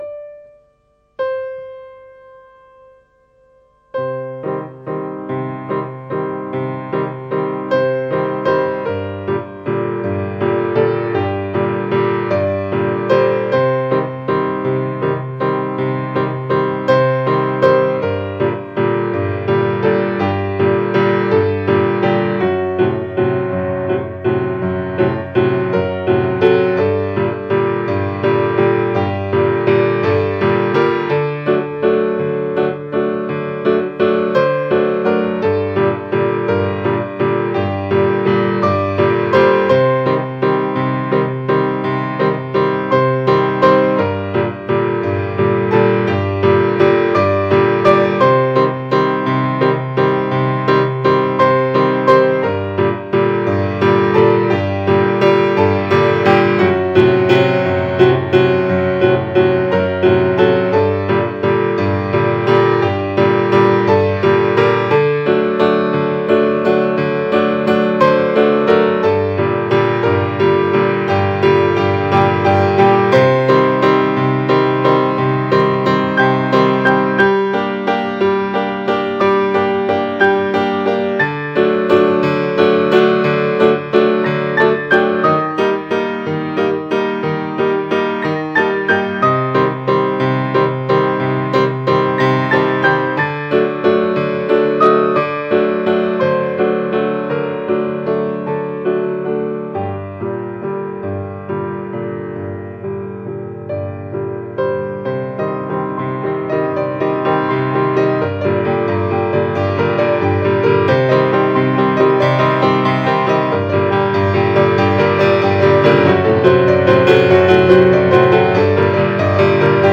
Bref, ubuesque et délicat à souhait, tout en mimétisme !